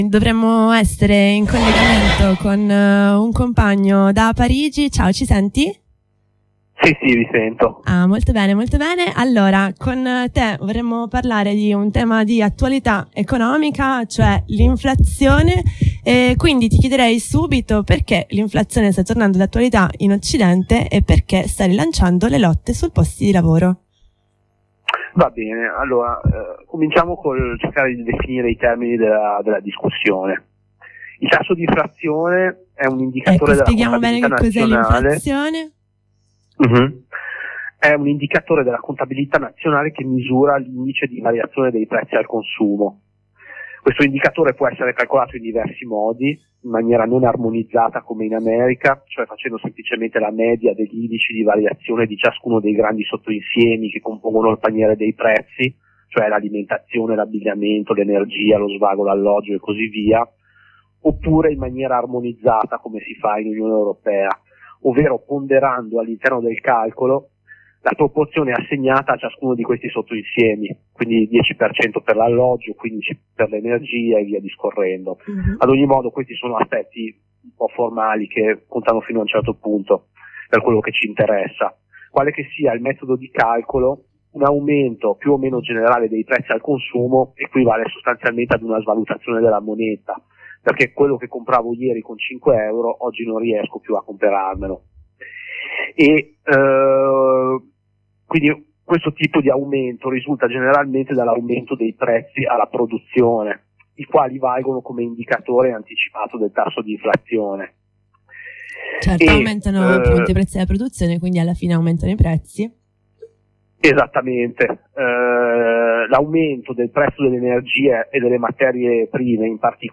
Ne abbiamo parlato con un compagno che sta a Parigi, in una lunga e interessante chiacchierata.